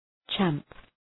Shkrimi fonetik {tʃæmp}